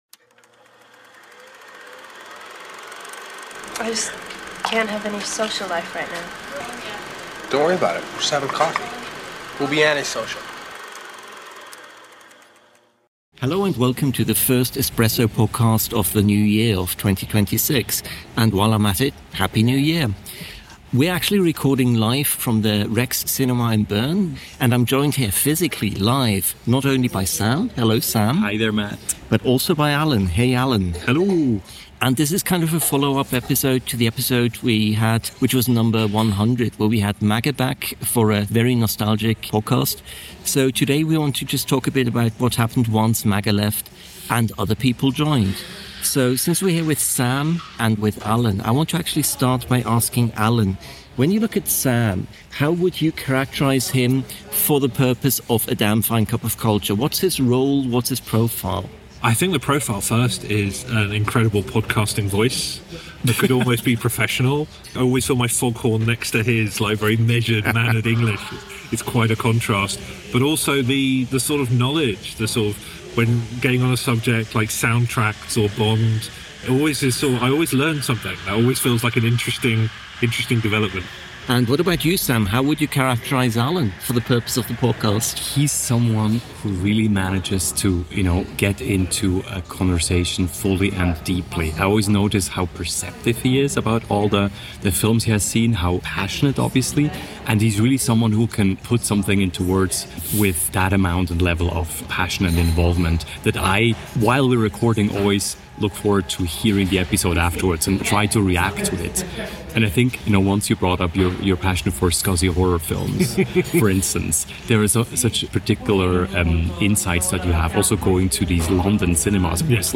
P.S.: A big shout-out to the wonderful REX cinema in Bern, Switzerland for letting us meet up at their bar in early January (you can hear the gentle noise of other cinemagoers, glasses and espresso cups in the background) to record our conversation!